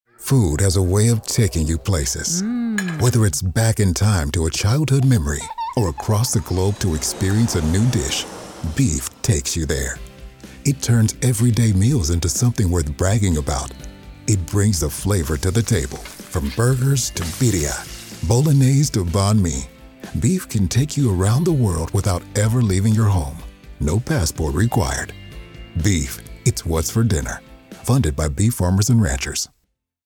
National Radio Ads